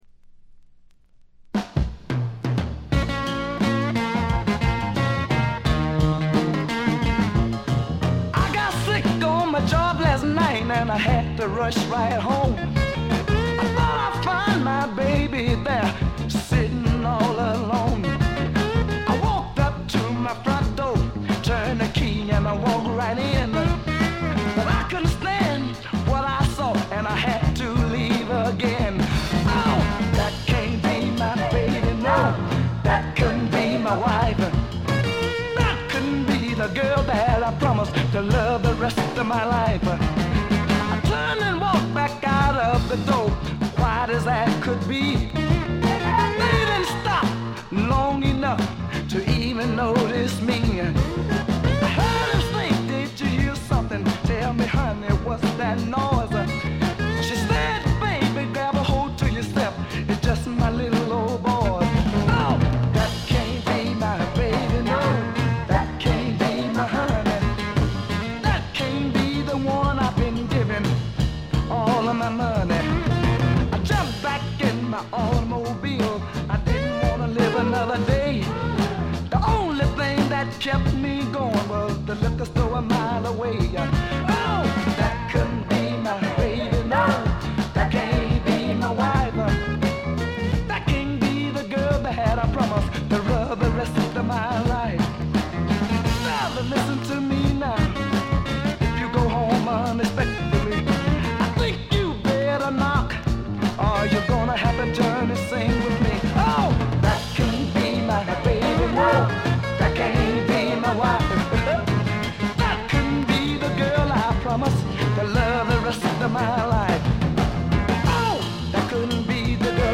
ところどころでチリプチ。
いうまでもなく栄光のサザンソウル伝説の一枚です。
試聴曲は現品からの取り込み音源です。